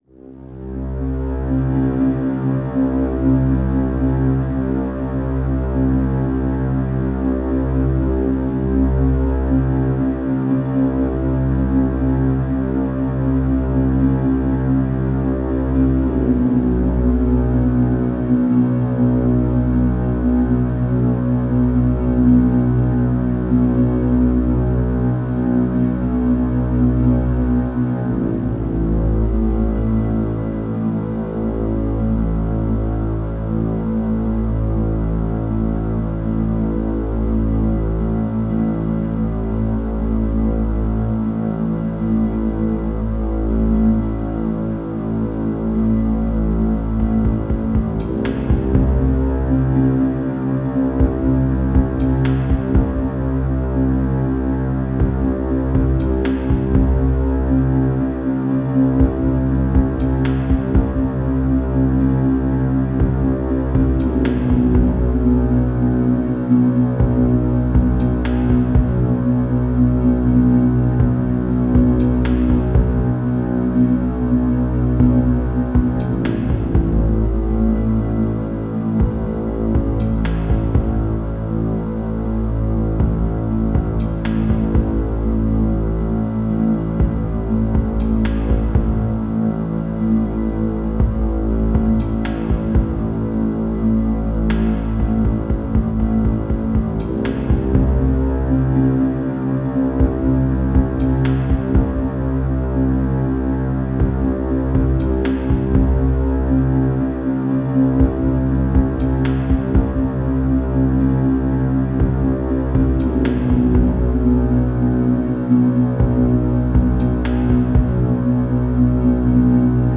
劇伴